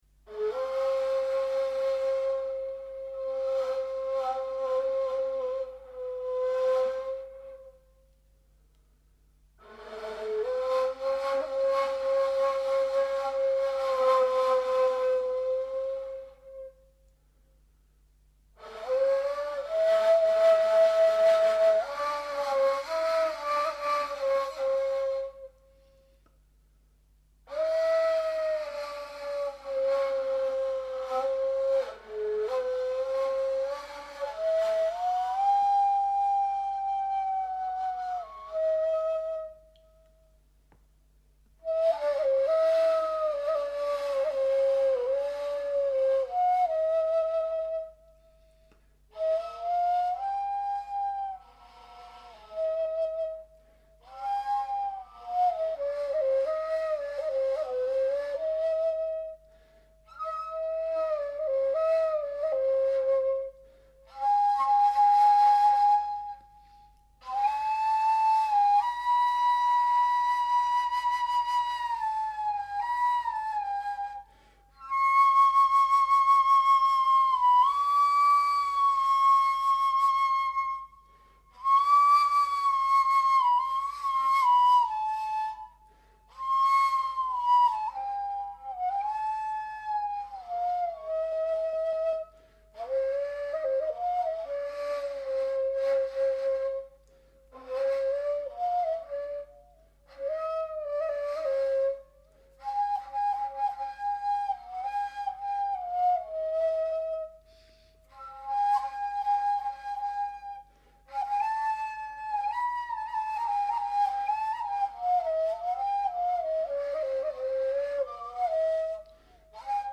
Ney
Sie weist einen sehr markanten Obertonklang auf, und wurde darum auch von jeher in der islamischen Mystik sehr geliebt.
Hier eine Aufnahme von dem Neyzenbaşı, dem Oberhaupt der Mevlevî-Derwisch Flötenspieler, unser verehrter Freund Niyazi Sayın:
Die Kunst des Neyspiels liegt vor allem in dem Entfalten von sphärischen Klangelementen, die Weite und Intension evozieren. Niyazi Sayın ist ein Meister des klaren Klanges, den er aber durch feinste Vibrationen, hervorgebracht mittels präzisen Wangenbewegungen während des Blasens, in eine bebende Spannung versetzt.